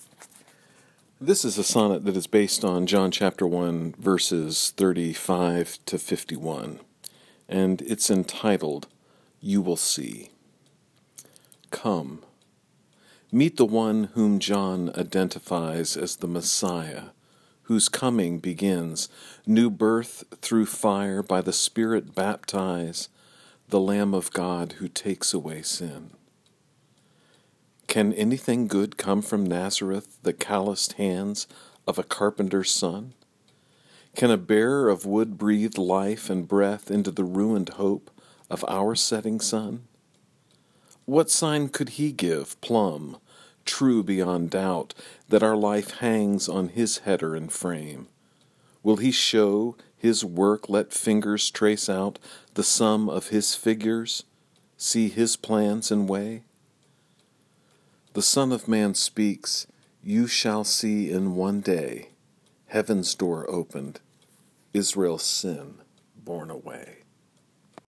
If helpful, you may listen to me read the sonnet via the player below.